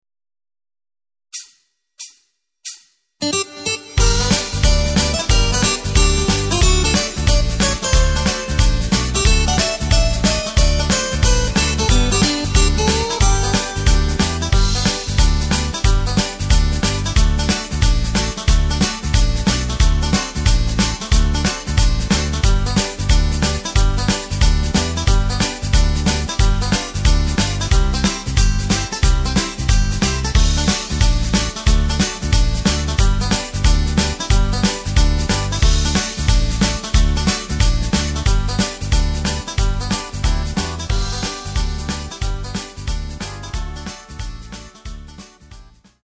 Takt:          4/4
Tempo:         182.00
Tonart:            G
Country-Beat aus dem Jahr 1995!
Playback mp3 Demo